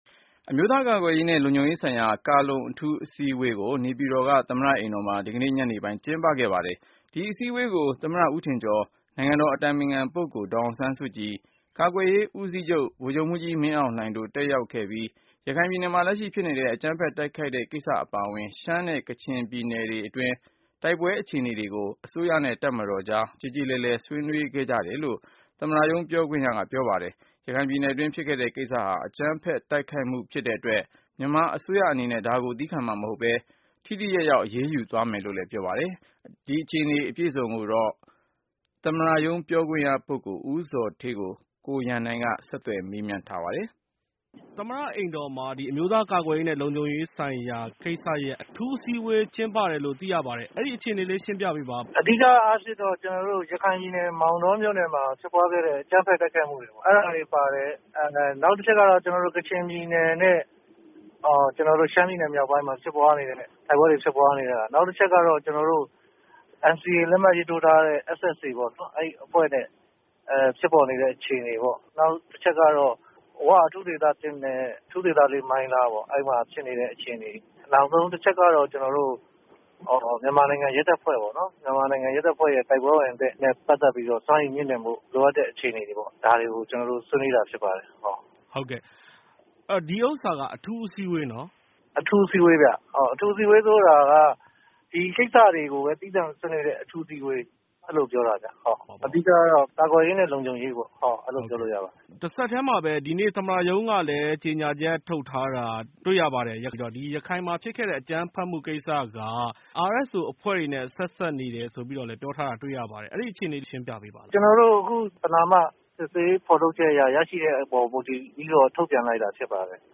ဆက်သွယ်မေးမြန်းထားတာဖြစ်ပါတယ်။